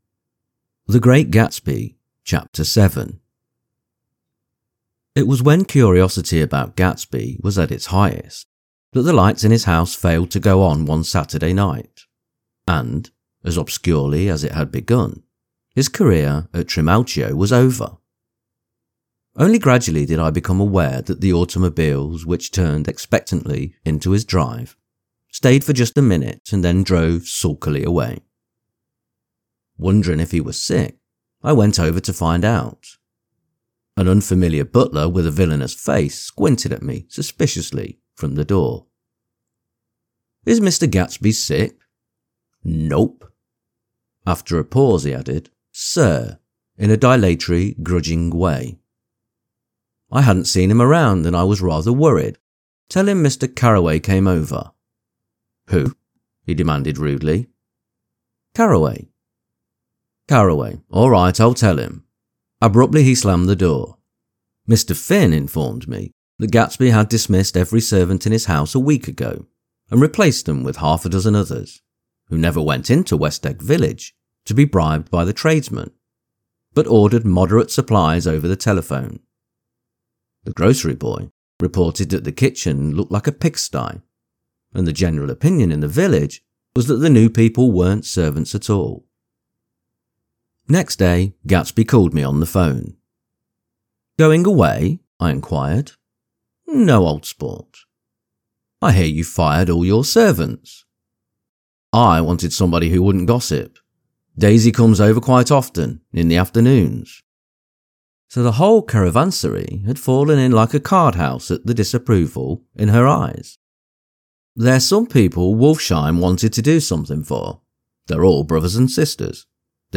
The Great Gatsby Audio-book – Chapter 7 | Soft Spoken English Male Full Reading (F.Scott Fitzgerald) - Dynamic Daydreaming